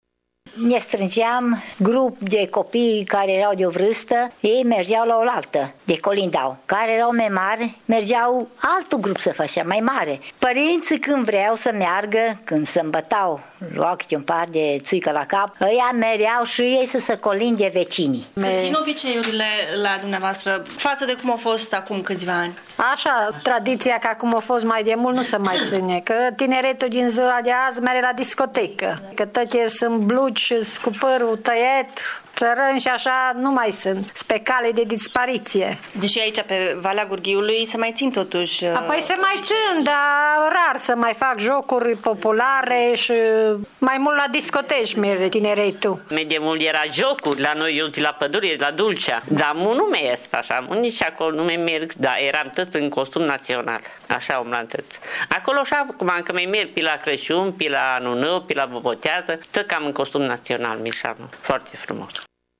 Aţi ascultat un reportaj